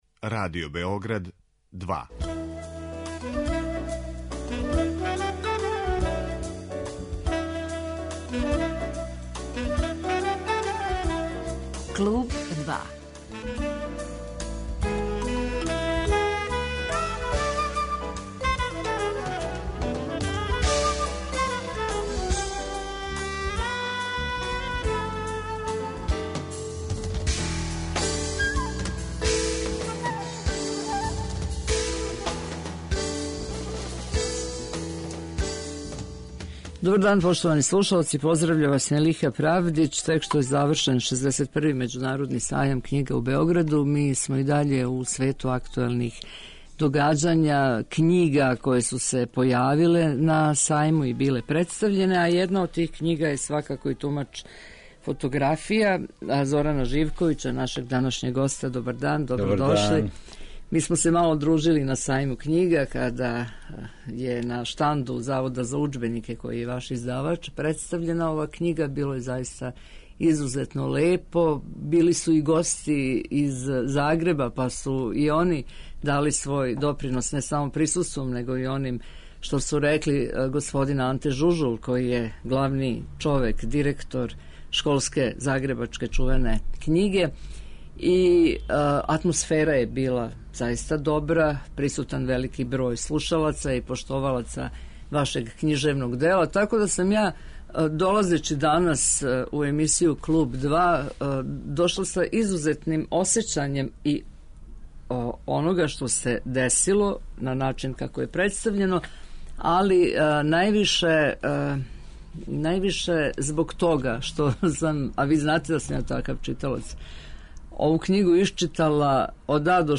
Гост 'Клуба' је књижевник Зоран Живковић.